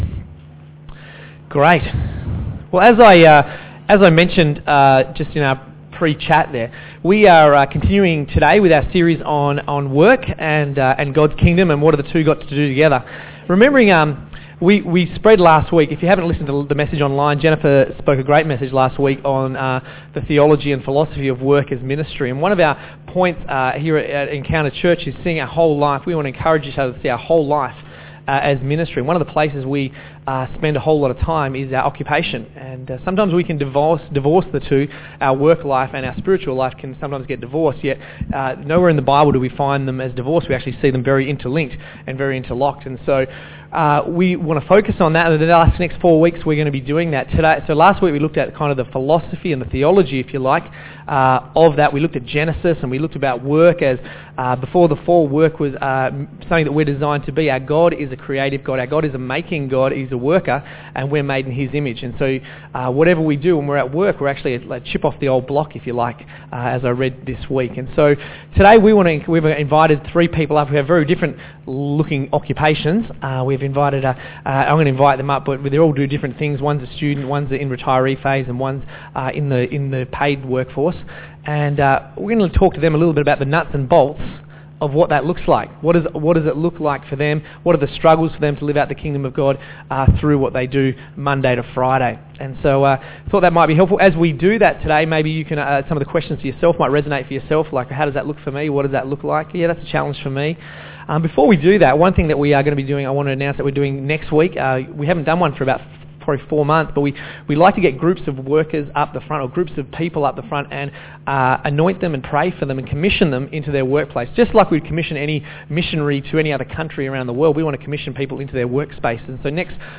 Group Interview – My Work is God’s Work